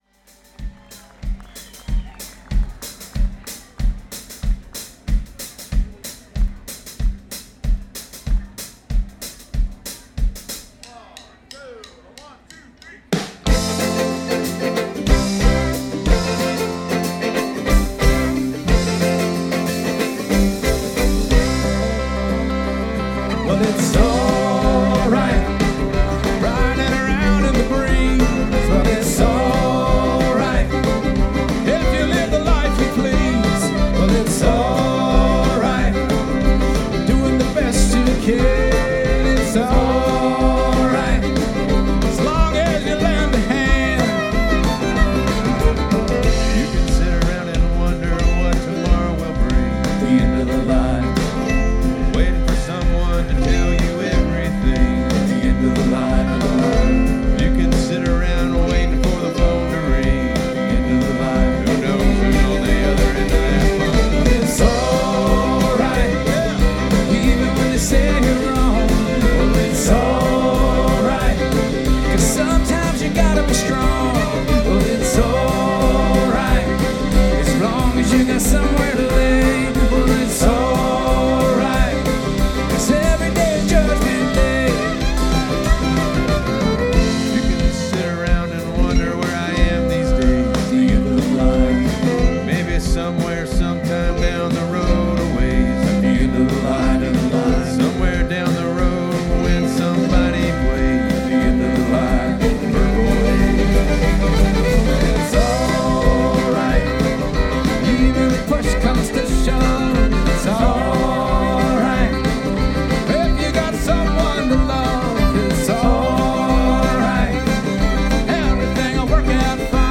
Brewers and Music Fest Huntersville, NC
Banjo, Guitar, Harmonica, and Vocals
Mandolin, Mondola and Vocals
Fiddle and Vocals
Bass, Guitar and Vocals
Percussion, Guitar, Dobro, Banjo, Whistling, Vocals,